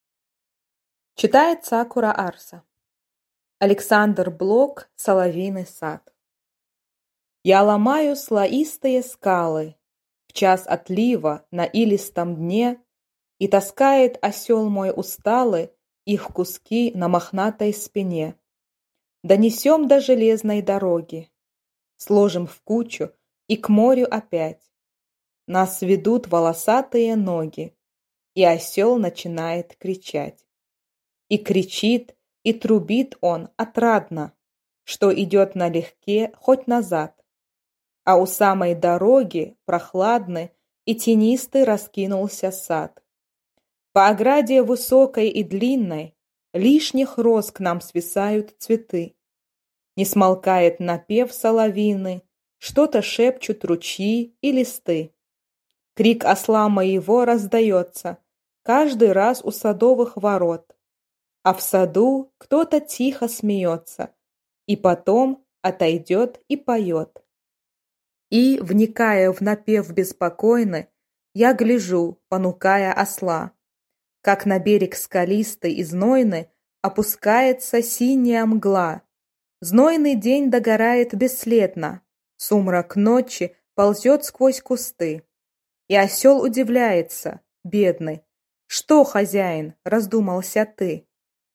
Аудиокнига Соловьиный сад | Библиотека аудиокниг